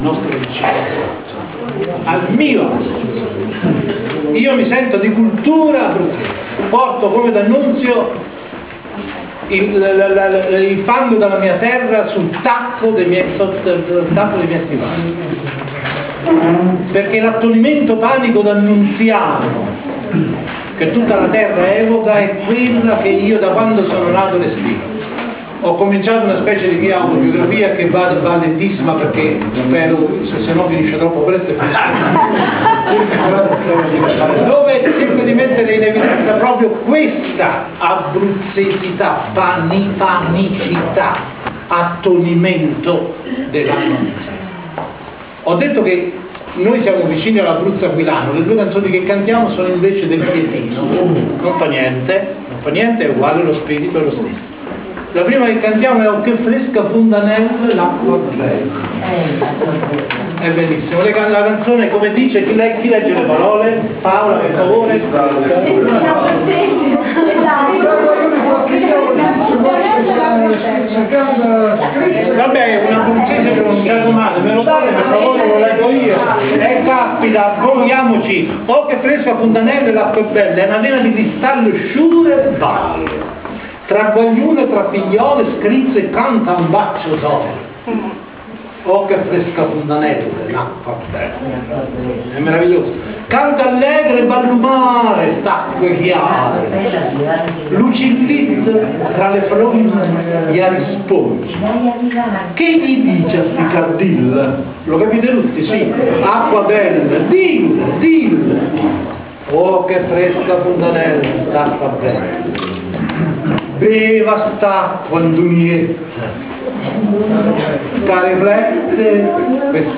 Civico Museo d’Arte Moderna di Anticoli Corrado, sabato 13 ottobre 2007